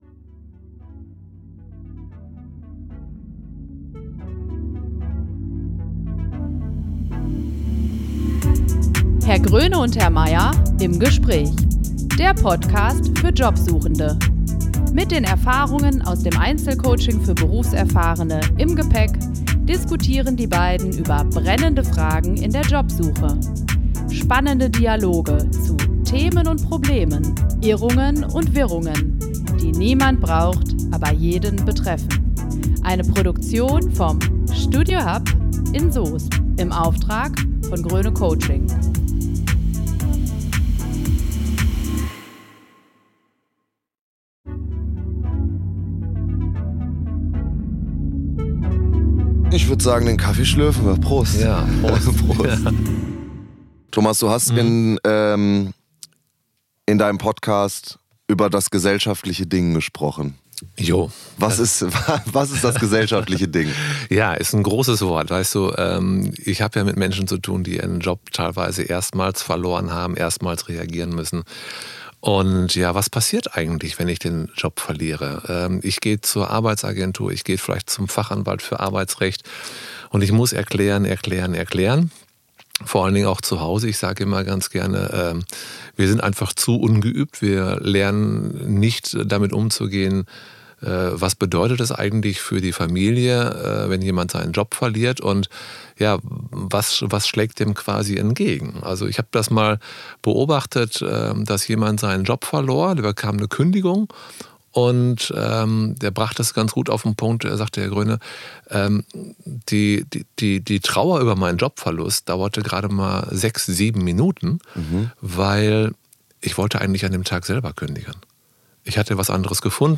plaudern beim Kaffee über das gesellschaftliche Ding !